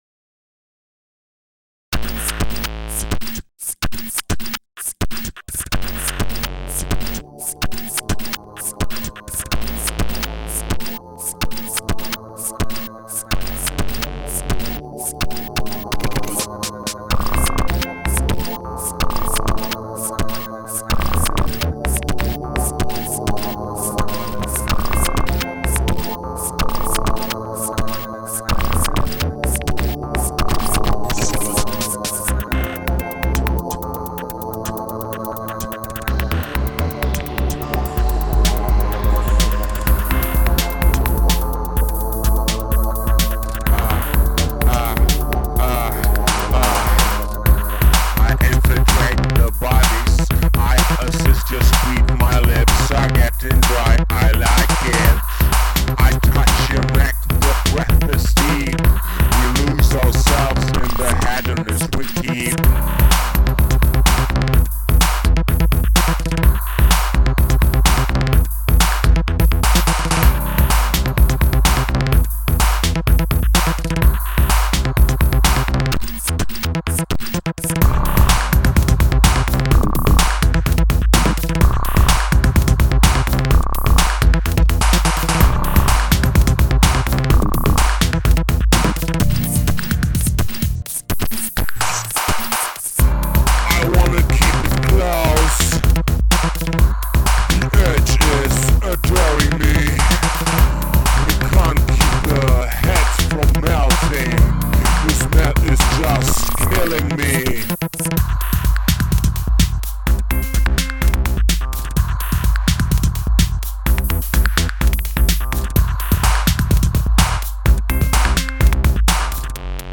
dirty elektro
"ja ... die sounds sind rawer und kratziger .
irgendwie rockiger deshalb."
Düster , kratzig , entrückt .
geiles Teil (wie eigentlich immer..), schön verfrickelt, geile Vocals.
die drums sind der hammer, und diese roboterhumanbeatbox prr prr pzz... fett.
sauber produziert, schickes gefrickel. aber nicht ganz mein style.